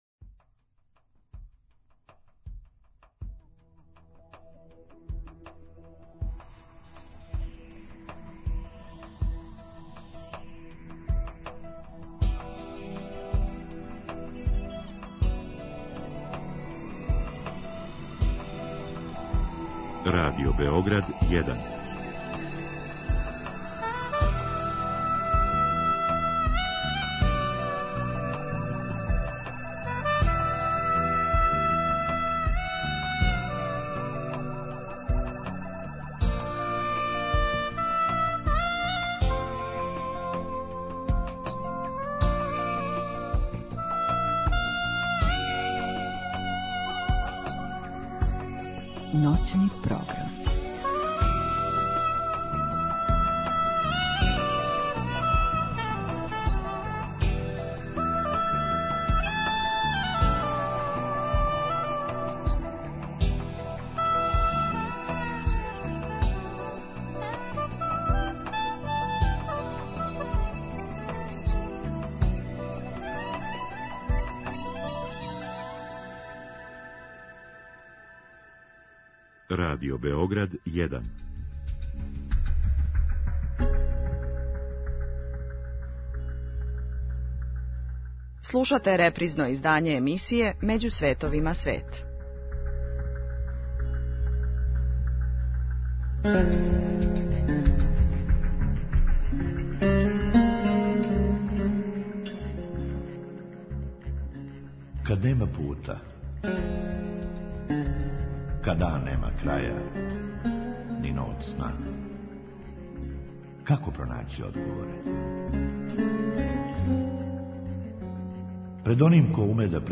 психолог и психотерапеут